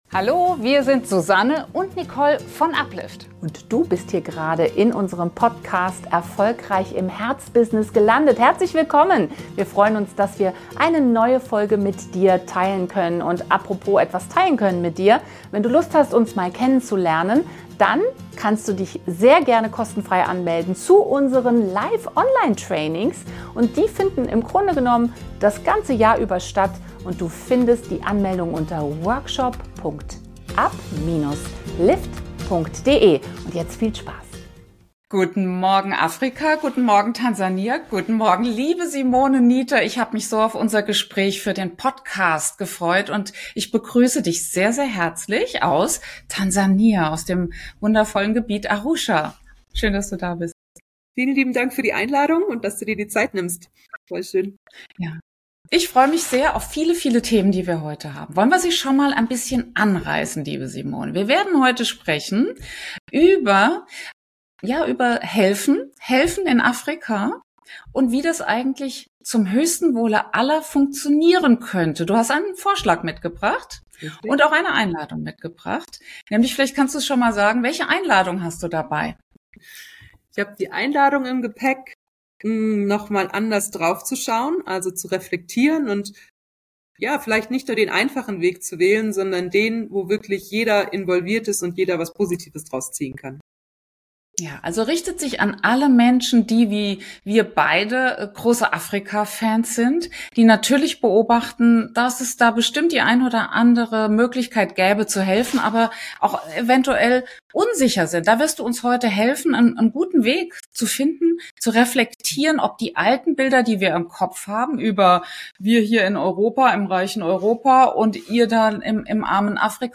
Erfolgreich im Herzbusiness Podcast - Interview mit der NGO-Gründerin